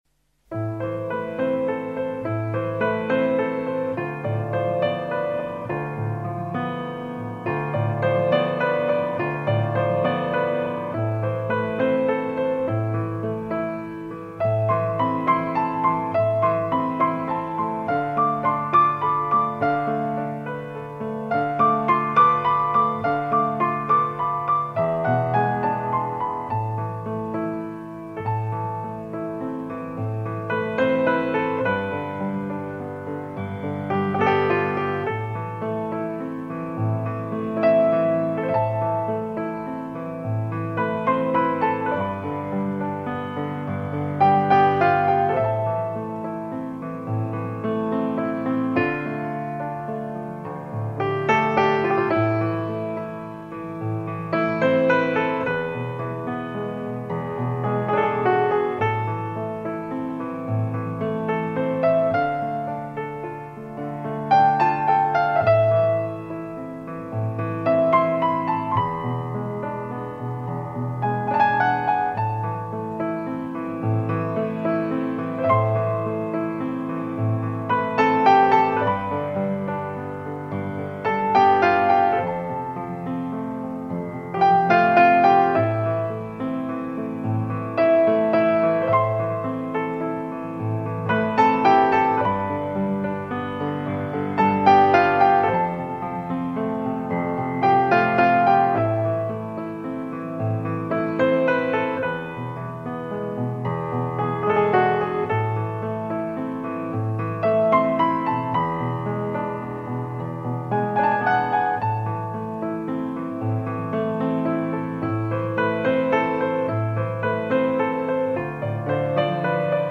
قطعه بیکلام